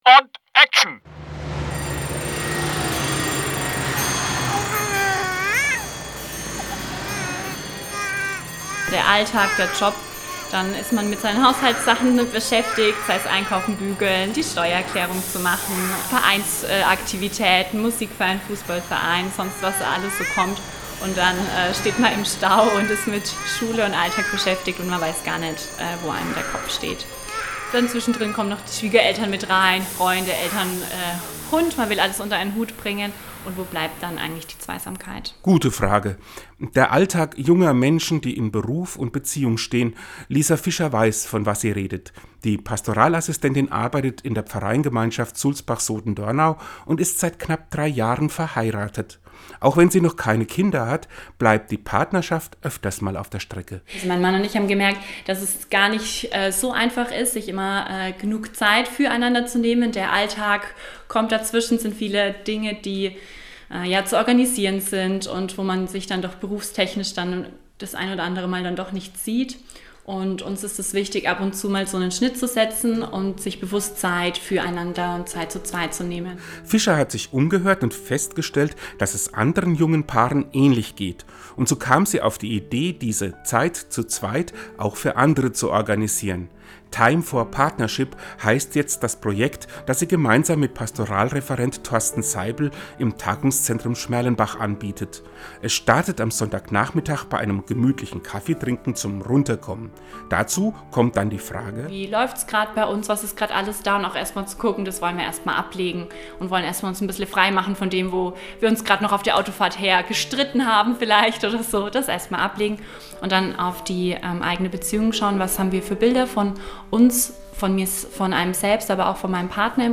Den Radiobeitrag finden Sie unten als Download!